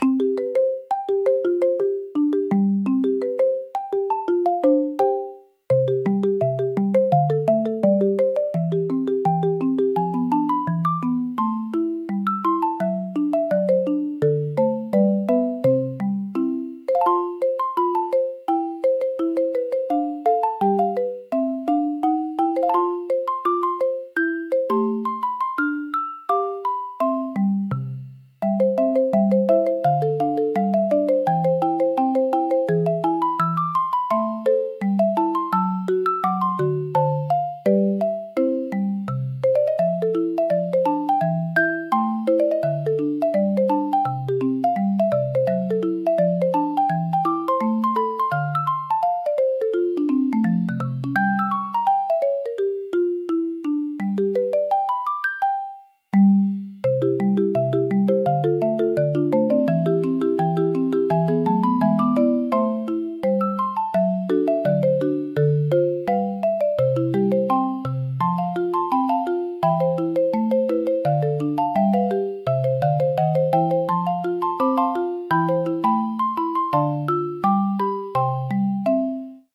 シンプルなメロディラインが心地よいリズムを生み、穏やかながらも前向きなムードを演出します。
ピアノの柔らかなタッチが集中をサポートし、疲れを癒す効果を発揮します。